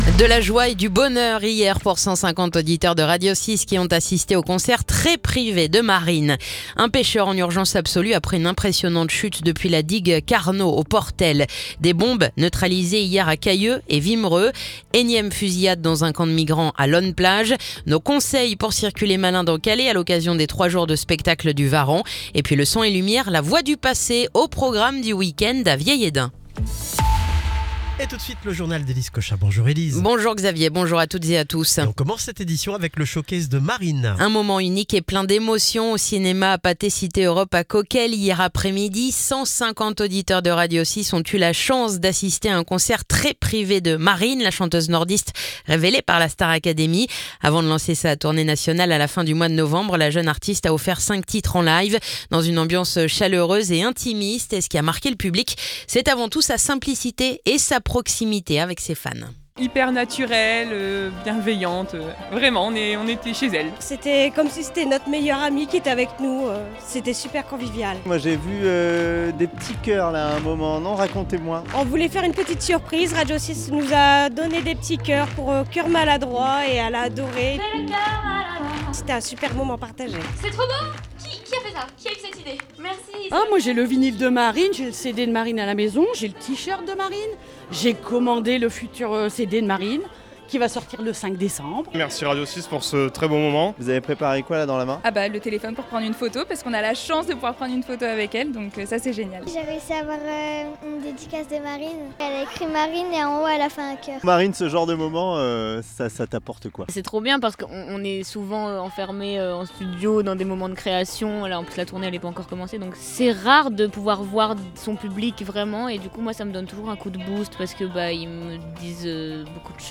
Le journal du jeudi 6 novembre